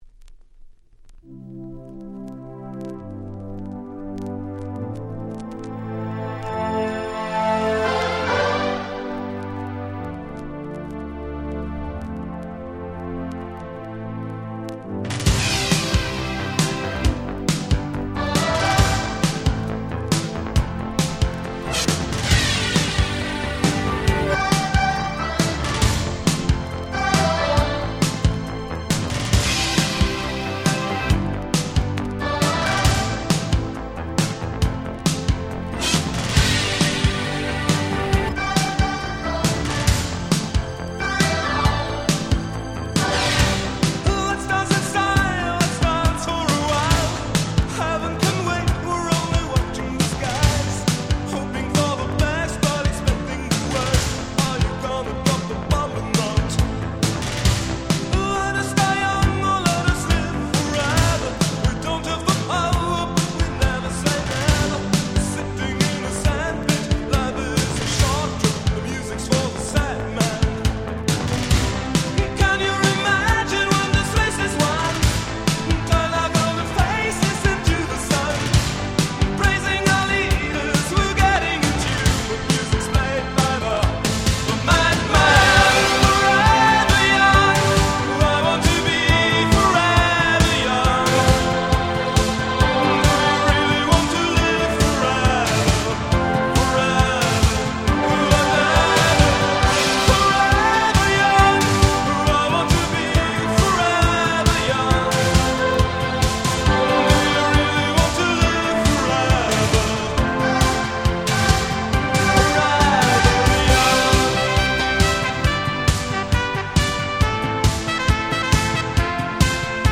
80's ロック ポップス